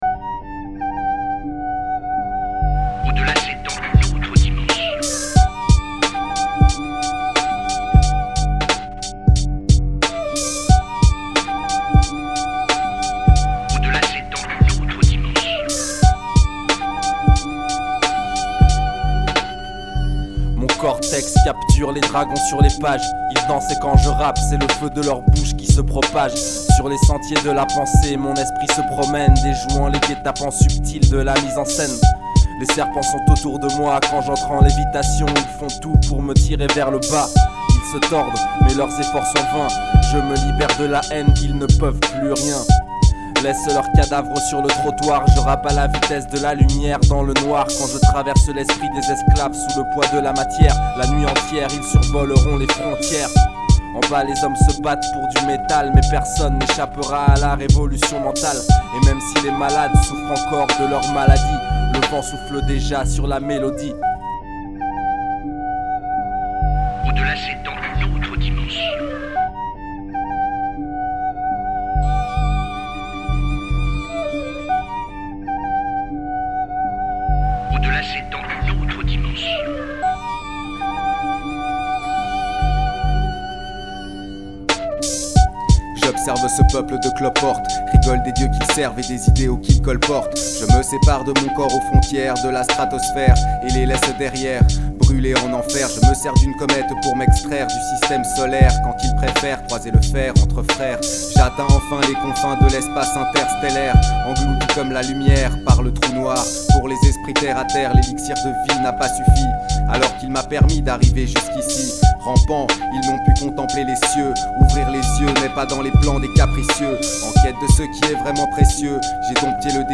mc